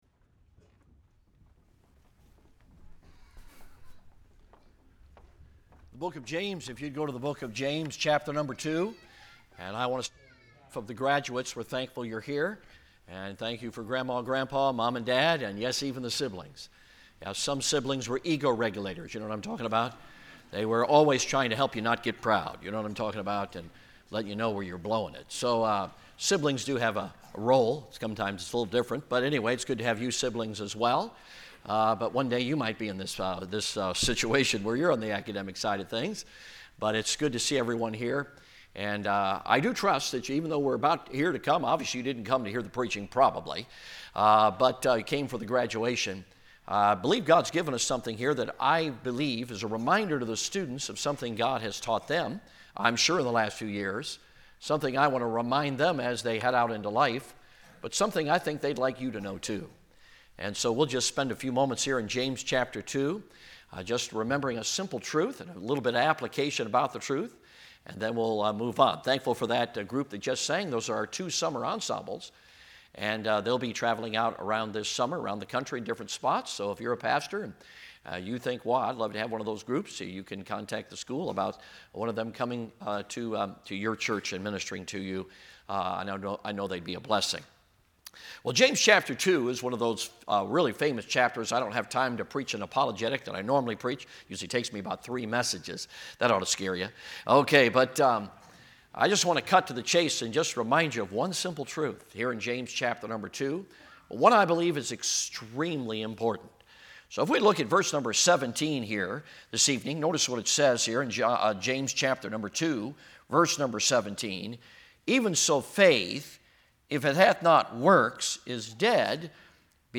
2025 BCM Commencement Service Archives - Falls Baptist Church